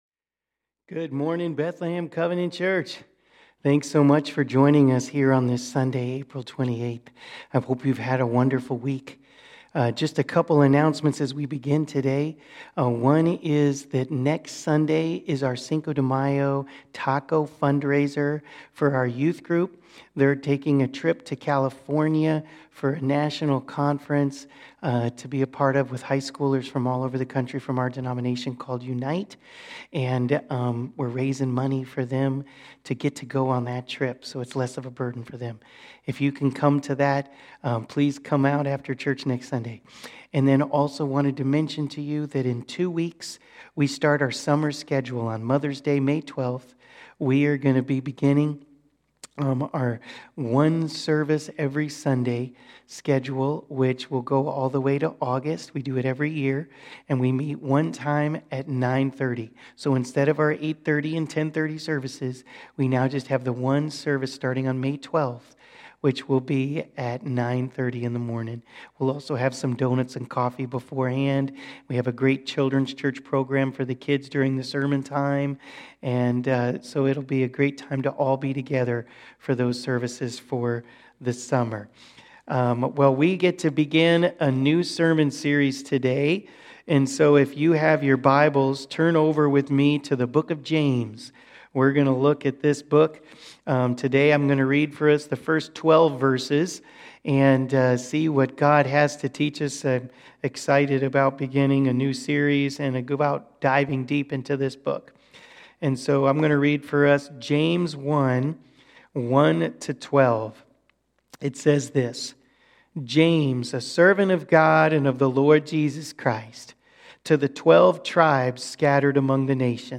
Bethlehem Covenant Church Sermons James 1:1-12 Apr 28 2024 | 00:31:57 1x 00:00 / 00:31:57 Subscribe Share Spotify RSS Feed Share Link Embed